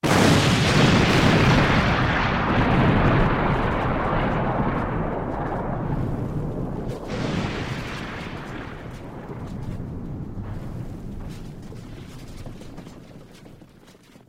Explosion 2